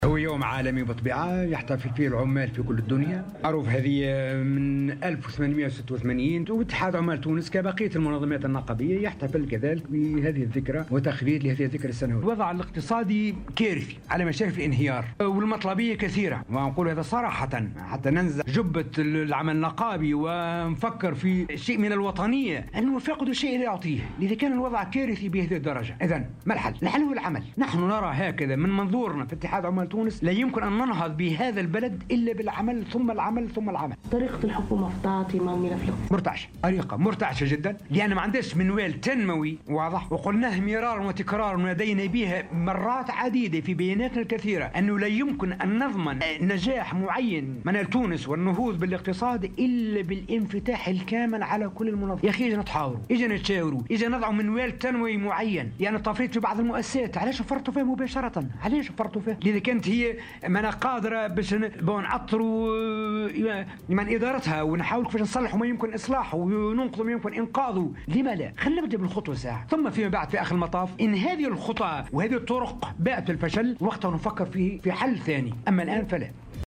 وأضاف في تصريح اليوم لمراسلة "الجوهرة أف أم" على هامش الاحتفال باليوم العالمي للشغل، أن الوضع الاقتصادي كارثي، وأنه لابد من منوال تنموي قادر على توفير أكبر عدد ممكن من مواطن الشغل، منتقدا أيضا سياسة التفويت في المؤسسات العمومية.